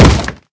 wood2.ogg